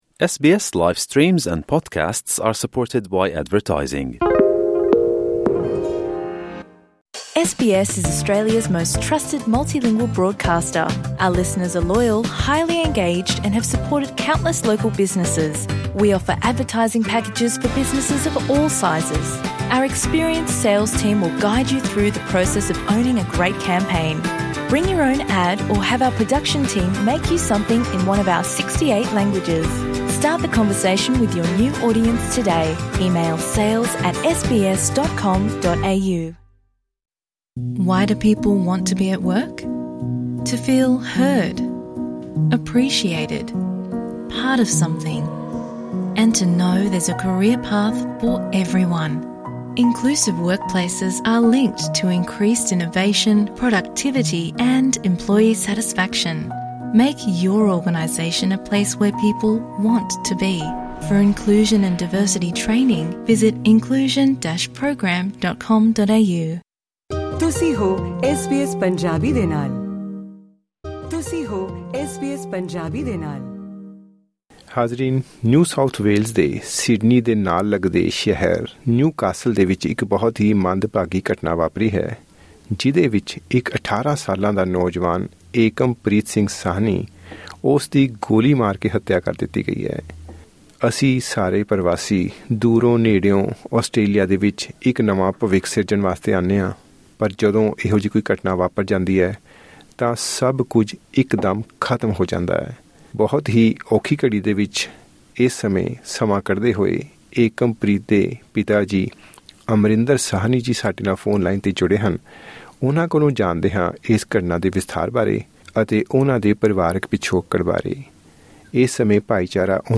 ਗੱਲਬਾਤ